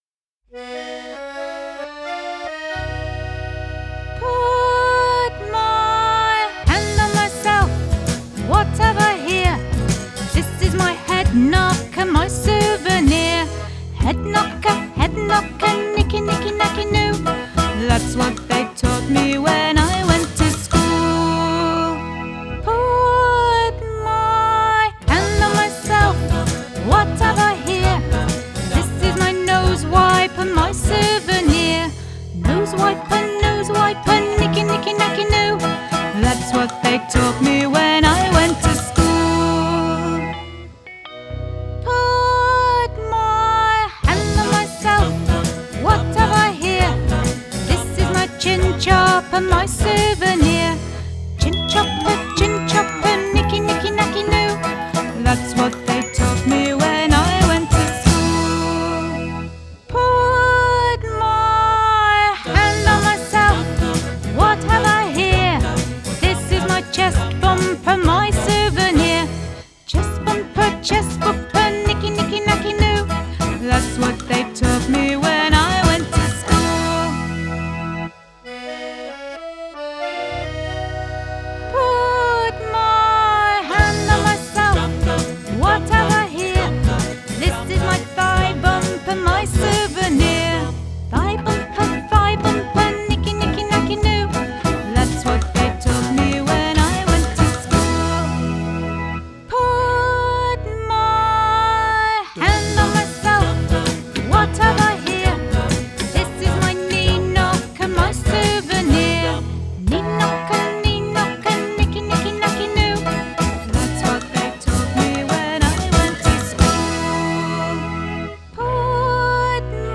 TraditionalFun & Punk